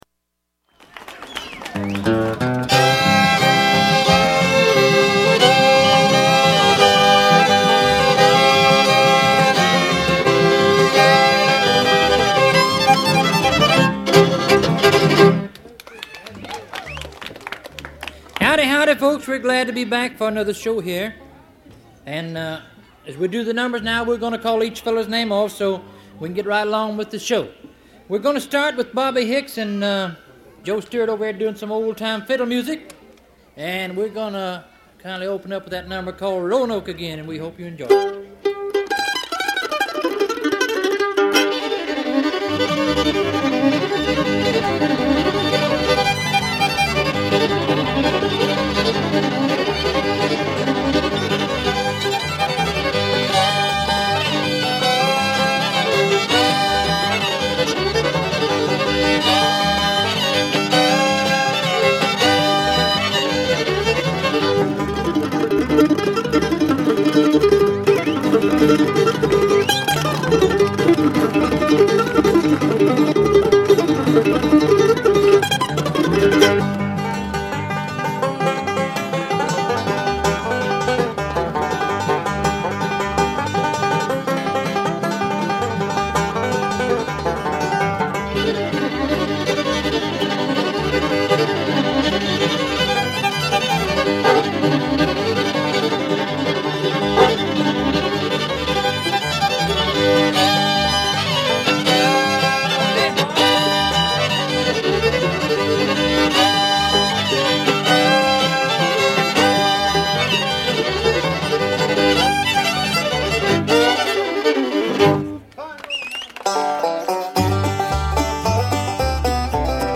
hard-driving bluegrass music